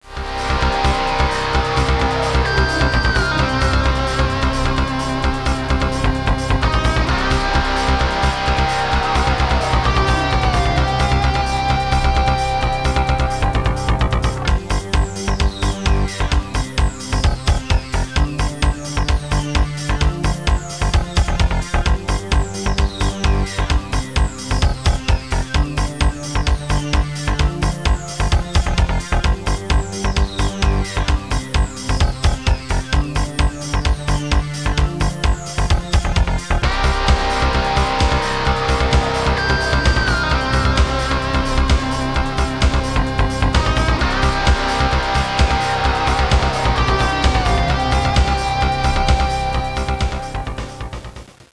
bike.wav